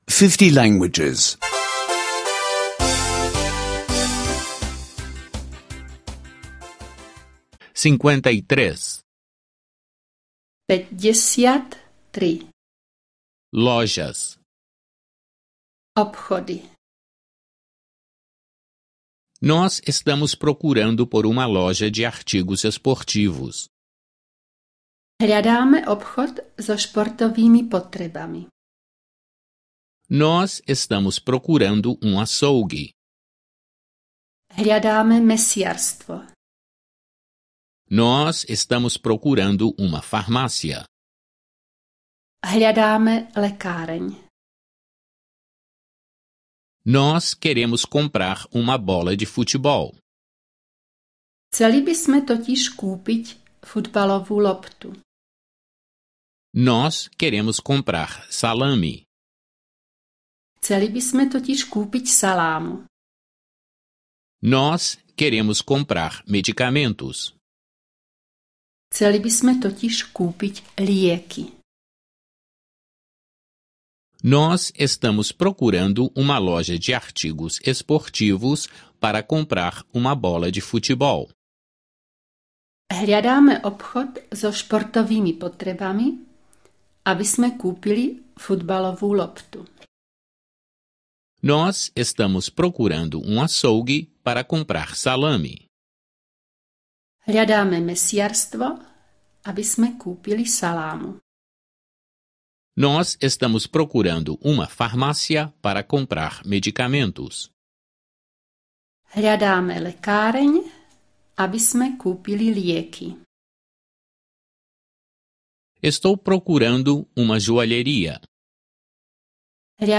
Aulas de eslovaco em áudio — escute online